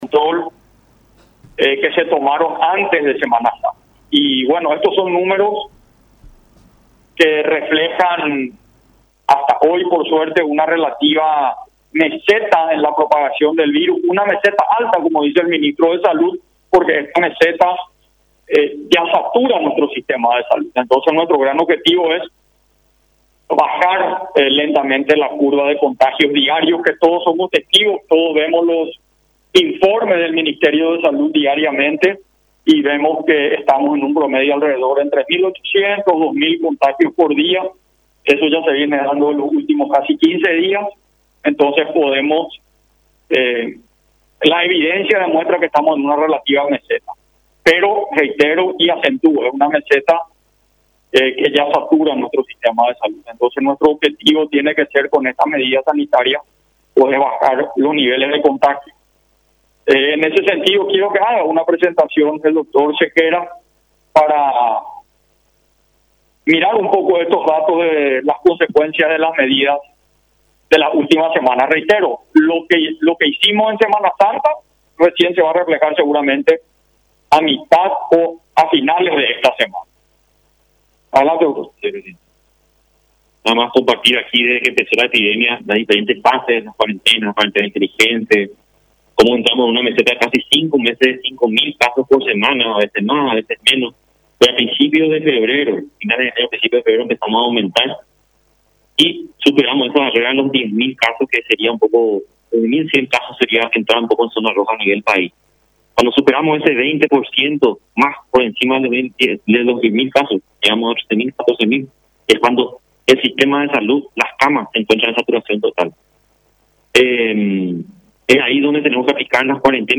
Abdo se refirió a la actual situación epidemiológica del Paraguay y, en ese sentido, en conferencia de prensa hizo un nuevo llamado a la ciudadanía para aferrarse al cumplimiento de los protocolos sanitarios a fin de reducir los contagios de coronavirus y brindar un respiro al sistema de salud.